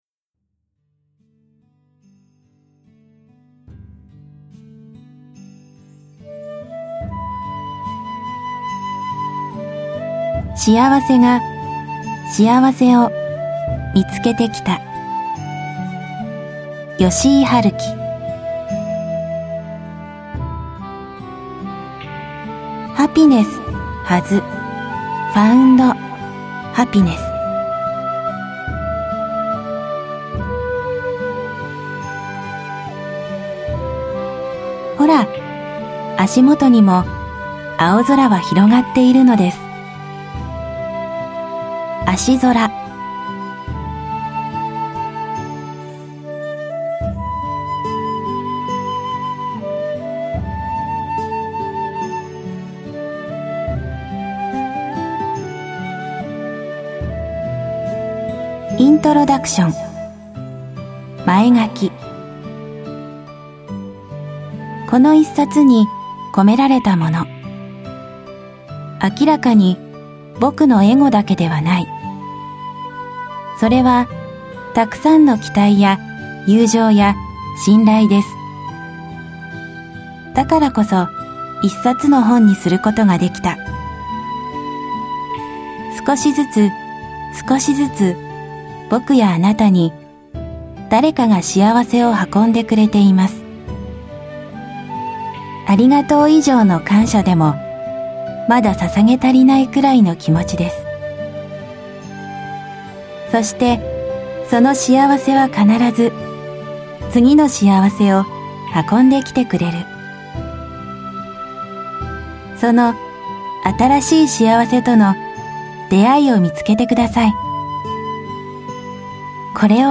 [オーディオブックCD] しあわせが、しあわせを、みつけてきた。